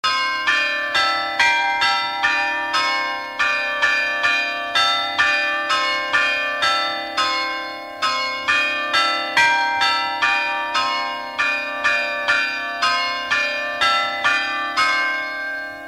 Résumé instrumental
Catégorie Pièce musicale inédite